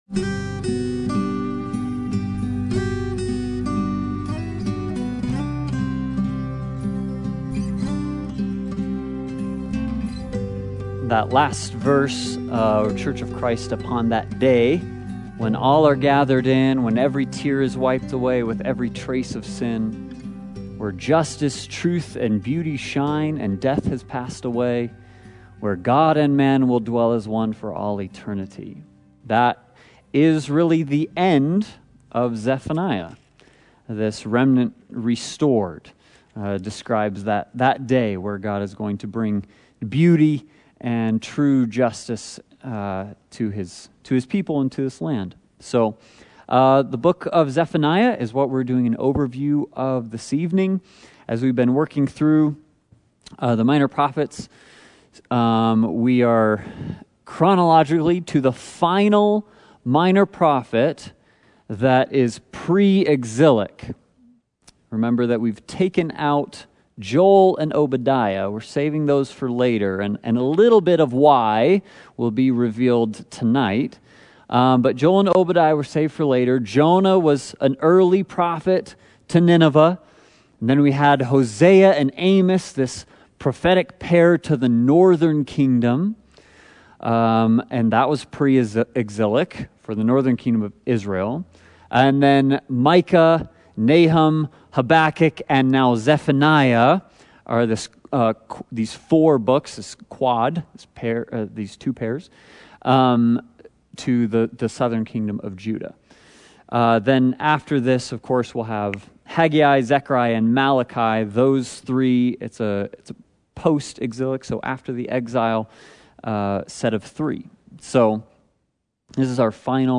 The Book of the Twelve Service Type: Sunday Bible Study « Isaac & Ishmael